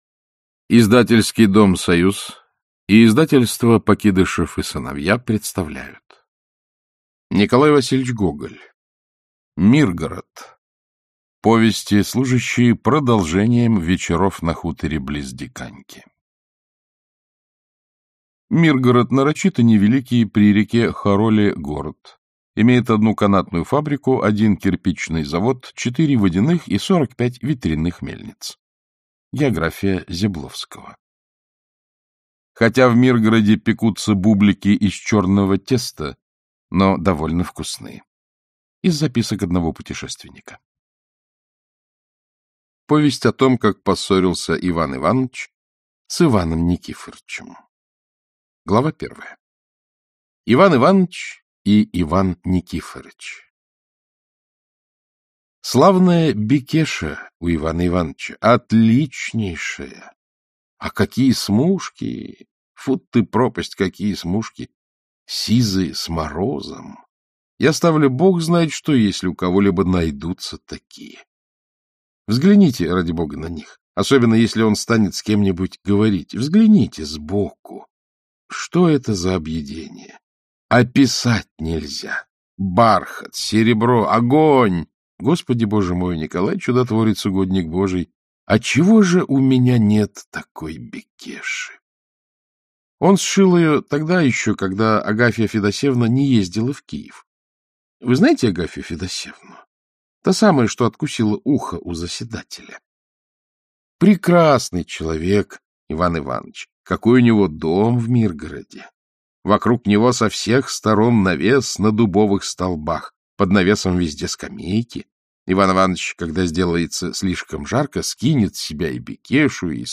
Аудиокнига Повесть о том, как поссорился Иван Иванович с Иваном Никифоровичем | Библиотека аудиокниг
Aудиокнига Повесть о том, как поссорился Иван Иванович с Иваном Никифоровичем Автор Николай Гоголь Читает аудиокнигу Александр Клюквин.